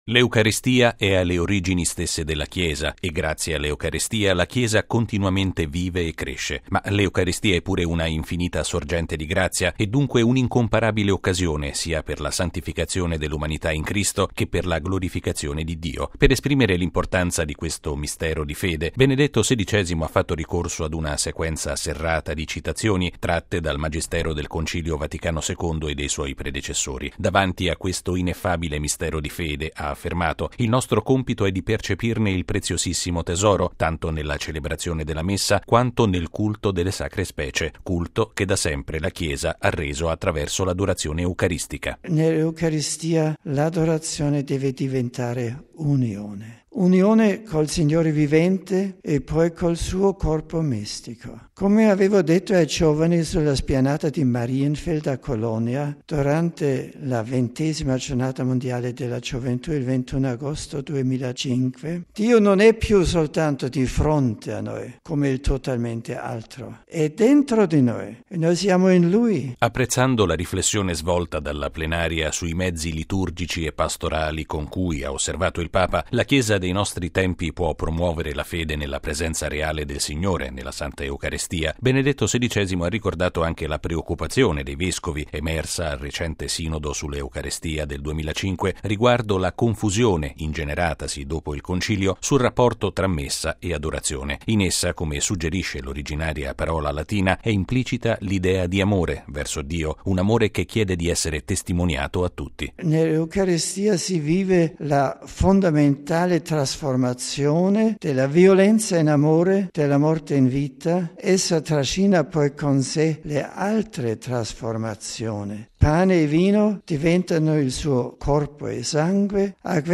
Il Papa ha poi concluso il suo discorso con un pensiero sul digiuno quaresimale: ci aiuti, ha detto, “ad allontanare da tutto ciò che distrae lo spirito”. Il servizio